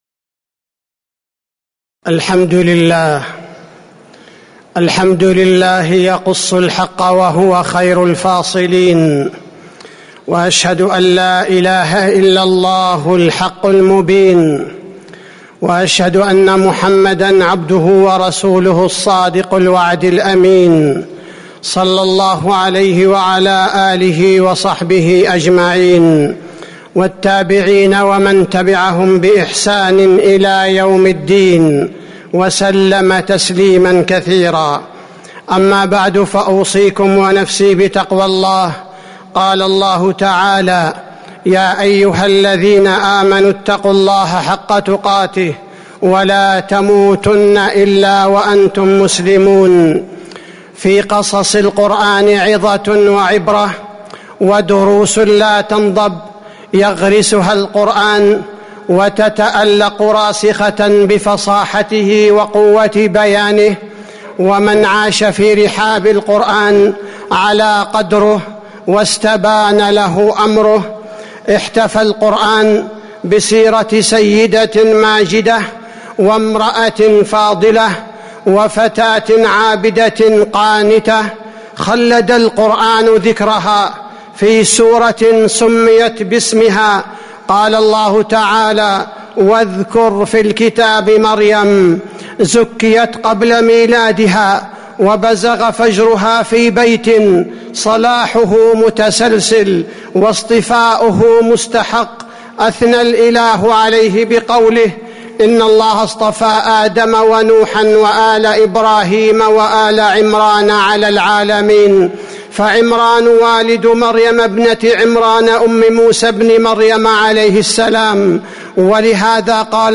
تاريخ النشر ٧ رجب ١٤٤٥ هـ المكان: المسجد النبوي الشيخ: فضيلة الشيخ عبدالباري الثبيتي فضيلة الشيخ عبدالباري الثبيتي دروس وعبر من قصة مريم